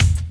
Wu-RZA-Kick 7.WAV